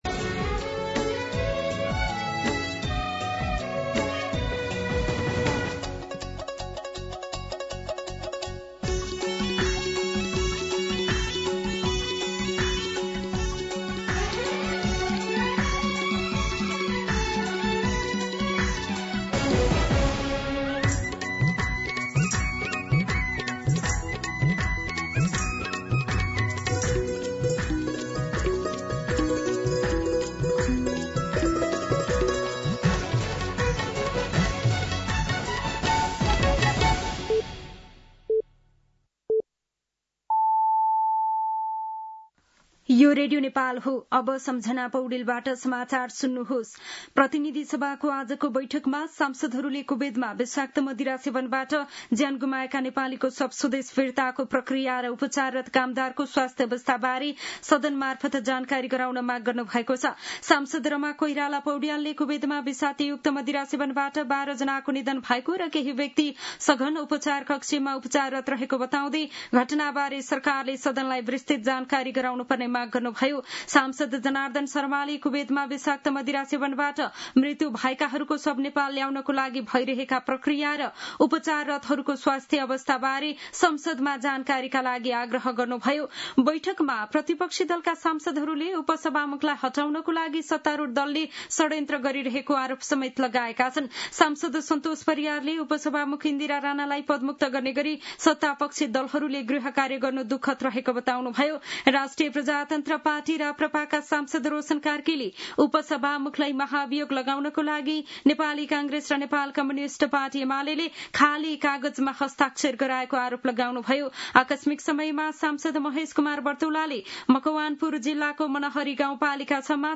दिउँसो ४ बजेको नेपाली समाचार : ४ भदौ , २०८२
4pm-News-05-4.mp3